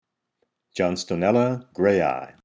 Pronunciation/Pronunciación:
John-sto-nél-la grày-i